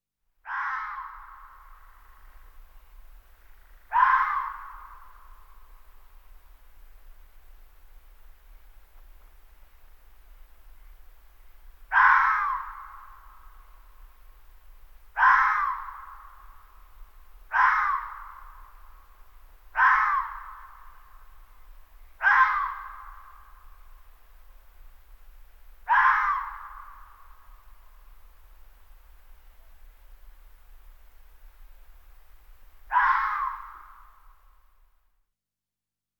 На этой странице собраны разнообразные звуки лисы: от реалистичных рычаний и тявканий до весёлых детских песенок.
Звук обычной лисы